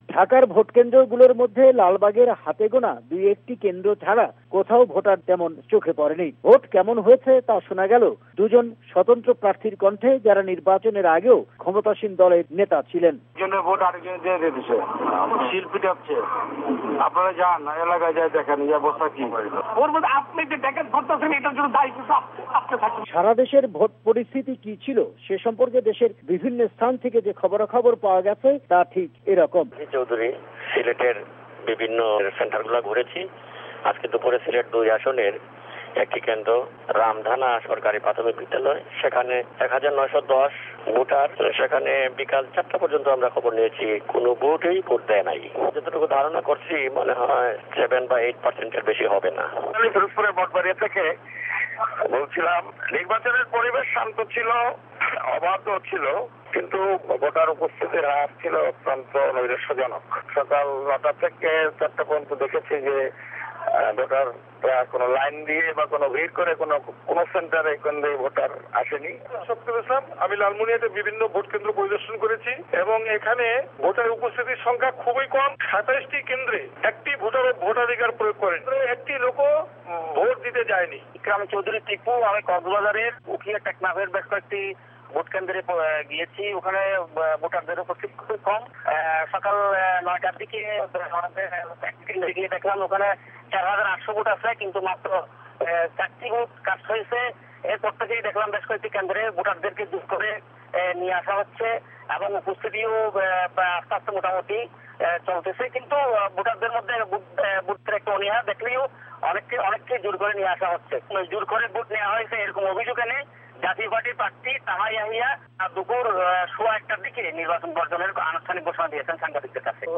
বাংলাদেশে দশম জাতীয় সংসদ নির্বাচনের ভোট হলো পাঁচ জানুয়ারী - VOA সংবাদদাতাদের রিপোর্ট